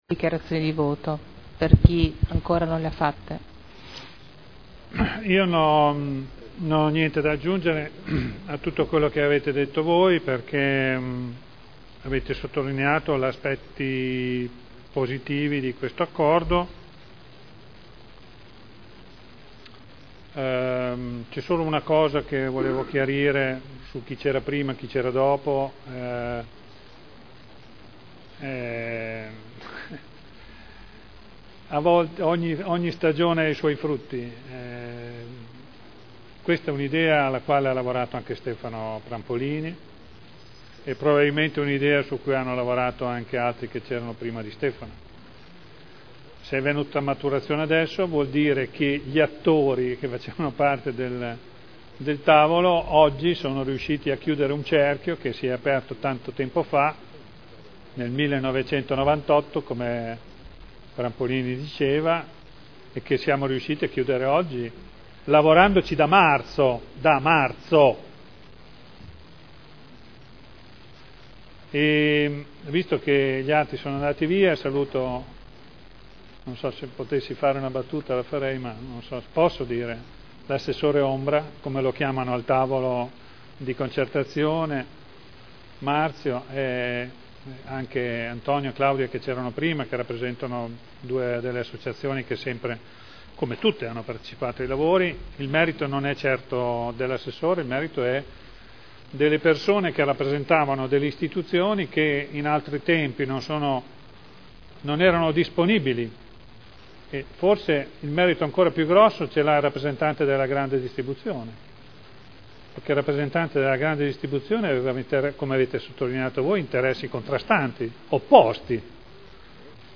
Seduta del 05/12/2011. Dibattito.